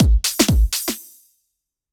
beat_sautant.wav